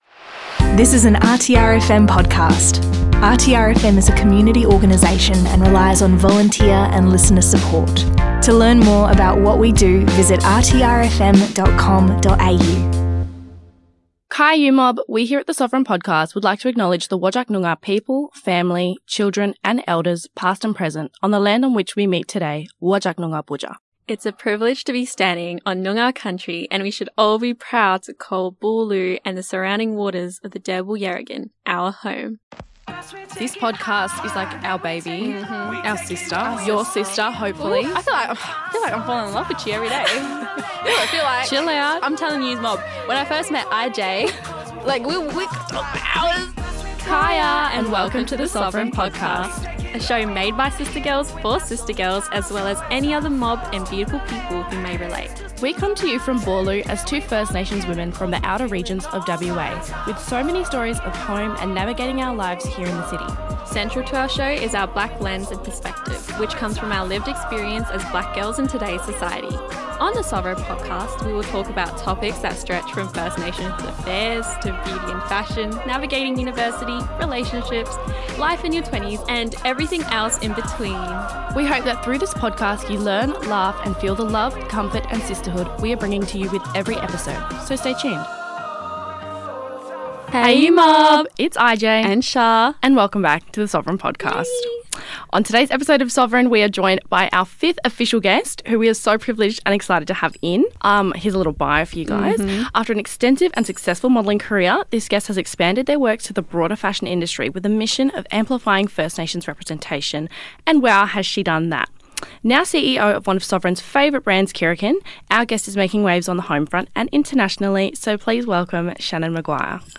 into the big red studio